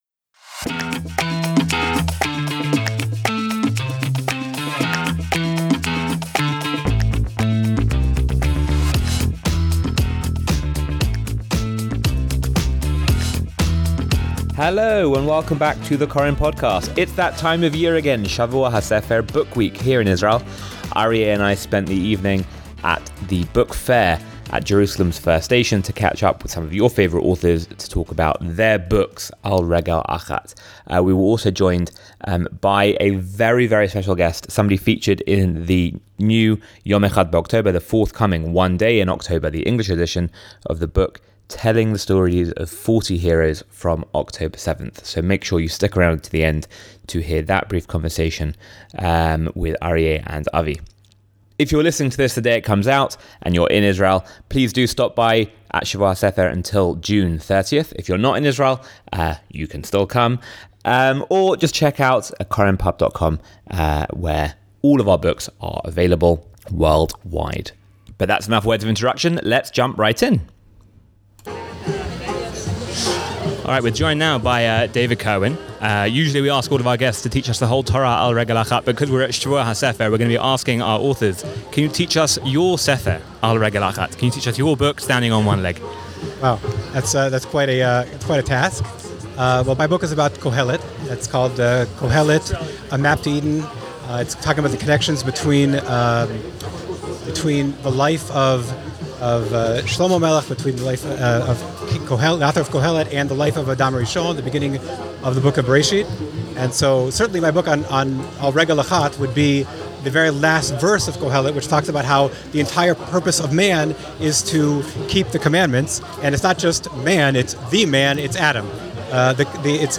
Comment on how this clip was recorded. Live From Shavua HaSefer!